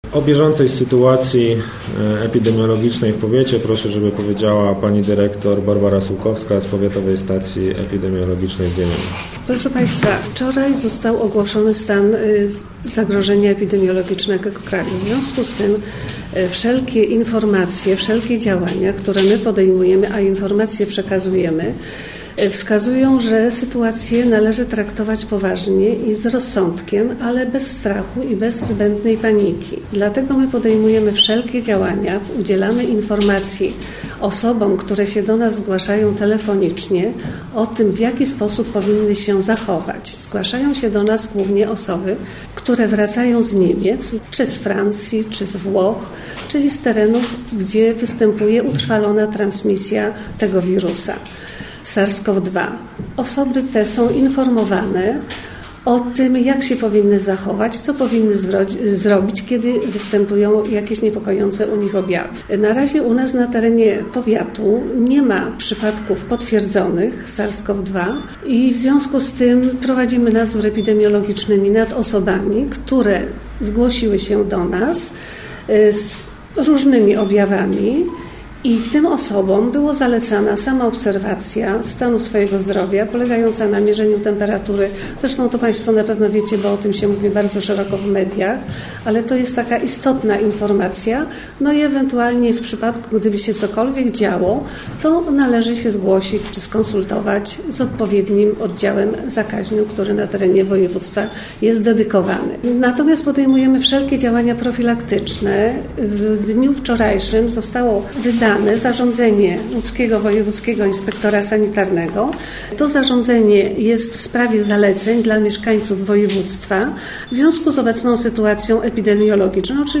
Dziś w południe w Starostwie Powiatowym w Wieluniu odbyła się konferencja prasowa zwołana przez wicestarostę Krzysztofa Dziubę.